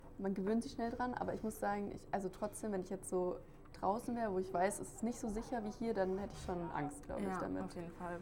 Blinde und sehbehinderte Guides begleiten Besuchende bei Dialog im Dunkeln durch lichtlose Räume.
DialogImDunkeln_Tour_mixdown_Gefuehle.mp3